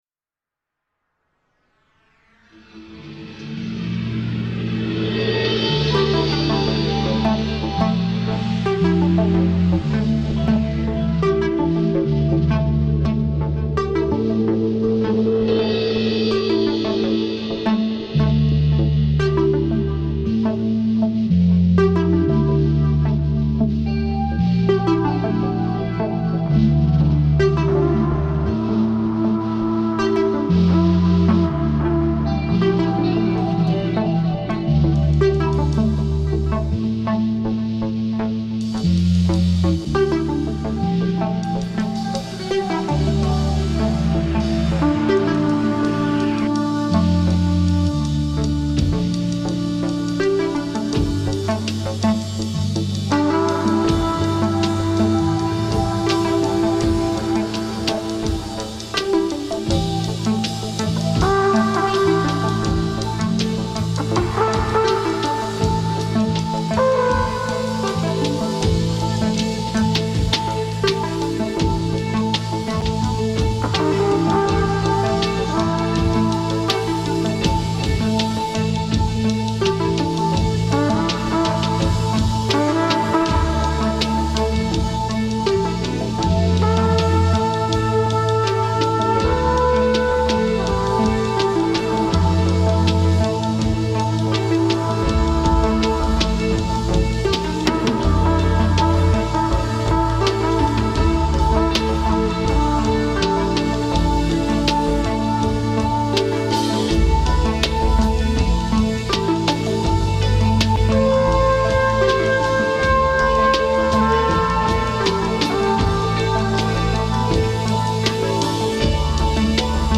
einer Session in Werl. Dies ist das Opener der Session.
Synthesizer
E-Gitarre, Flügelhorn
Bass, Samples
Schlagzeug